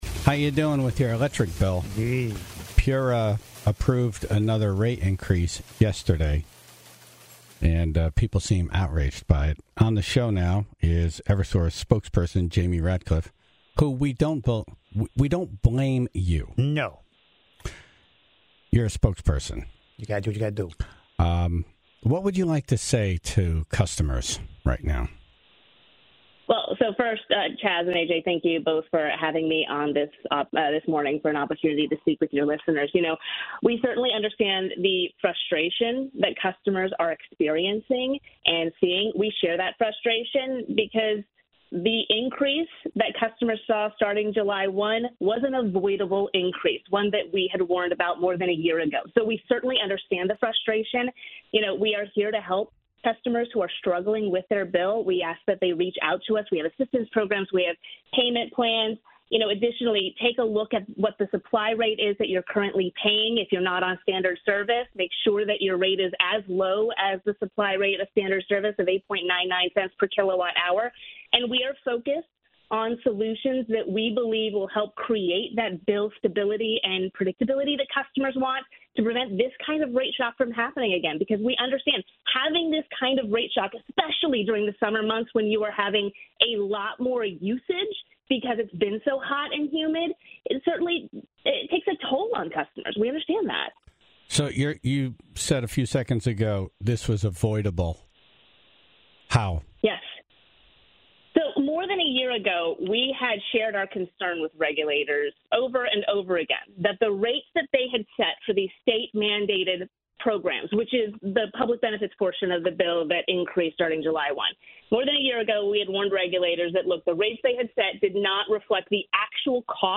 Shortly after her call, State Rep Vin Candelora responded to news that energy bills will be rising yet again in September, this time to fund public charging stations for electric vehicles.